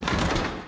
SFX_Motorcycle_PickUp_03.wav